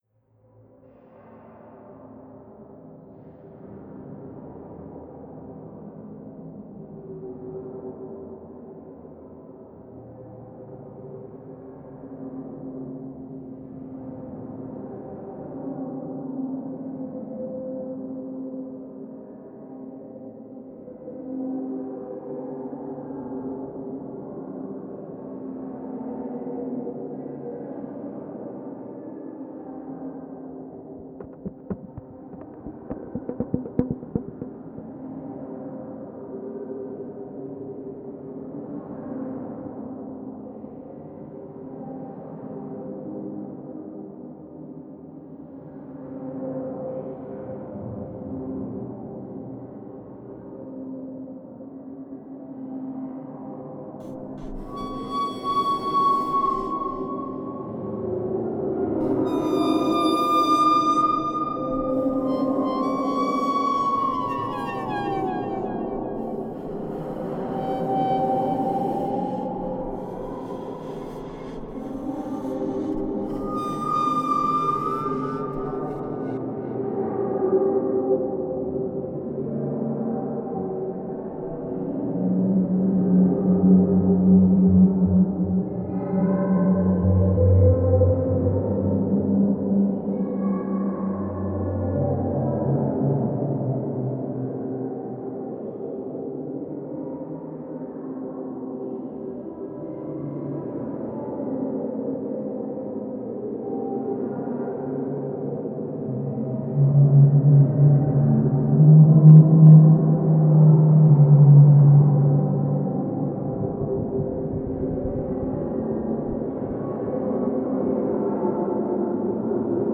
Composition mêlant le brouhaha d'une foule, des instruments acoustiques et de l'électronique.